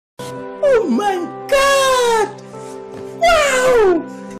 На этой странице собраны звуки с фразой «Oh my god» в разных эмоциональных оттенках: удивление, восторг, шок.